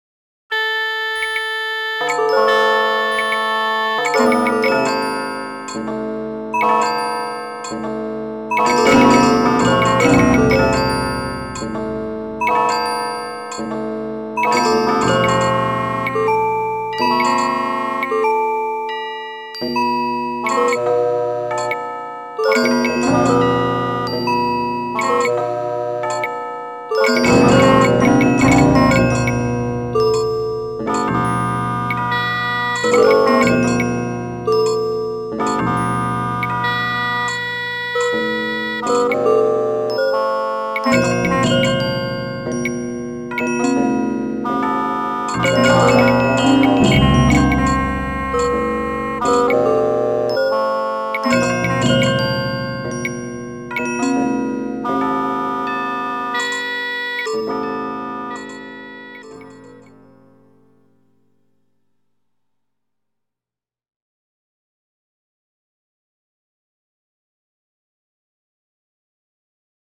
These mp3s were rendered to audio with the Roland Sound Canvas.
heavenly chimes scale with shanai etc
So, in these recordings, they fade out at the end of the clip.
heavenly_chimes_scale_with_shanai_etc.mp3